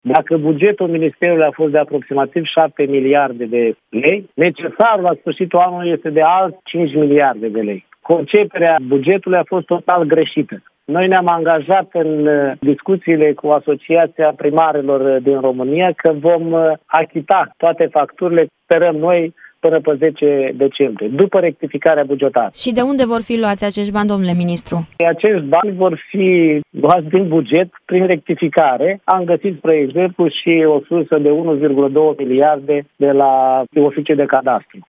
Rectificarea bugetară va fi făcută până la sfârșitul acestei luni, dă asigurări ministrul Dezvoltării, Ion Ștefan, la Europa FM.